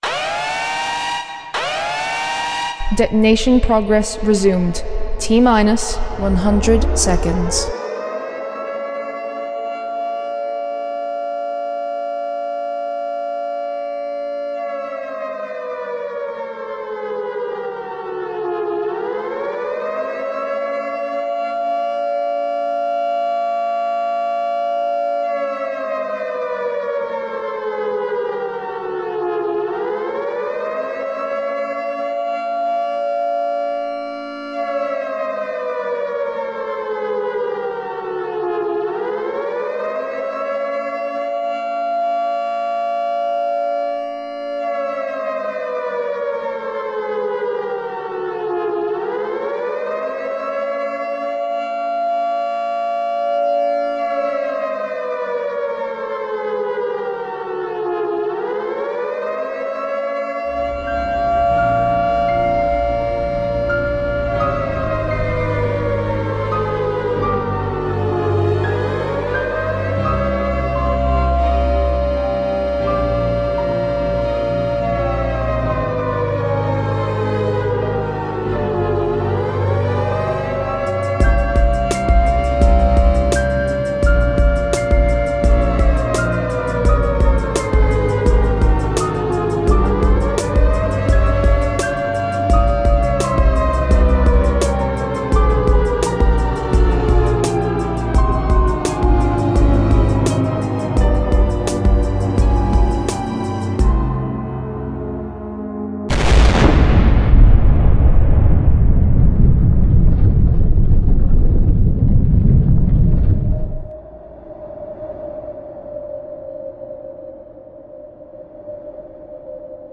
FemaleResume100.mp3